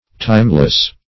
Timeless \Time"less\ (t[imac]m"l[e^]s), a.